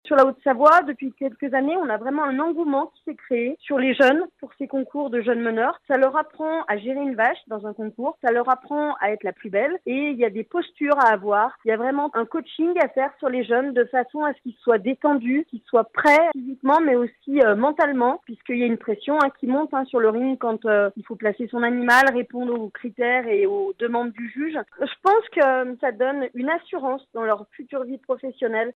productrice de fromage